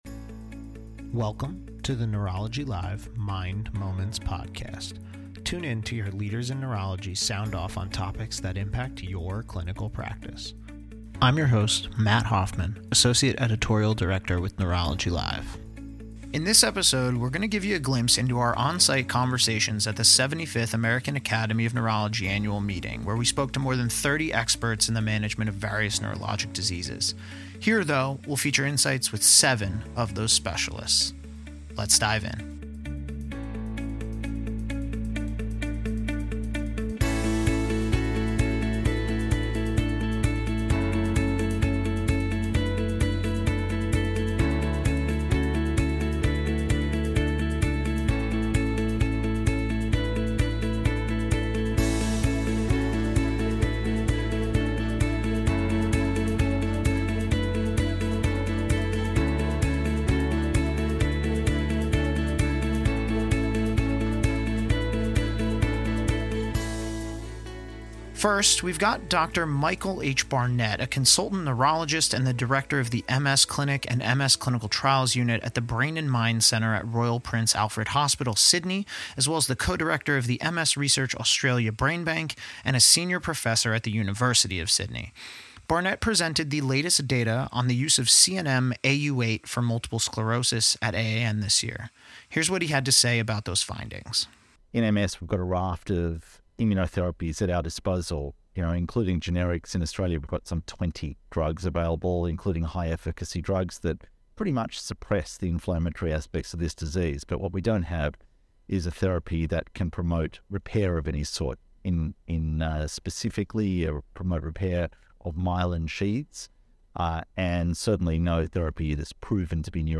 In this episode, we spoke with a variety of specialists in neurology who presented research and gave talks at the 75th American Academy of Neurology Annual Meeting, held April 22-27, 2023, in Boston, Massachusetts.